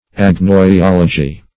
Search Result for " agnoiology" : The Collaborative International Dictionary of English v.0.48: Agnoiology \Ag`noi*ol"o*gy\ ([a^]g`noi*[o^]l"[-o]*j[y^]), n. [Gr.
agnoiology.mp3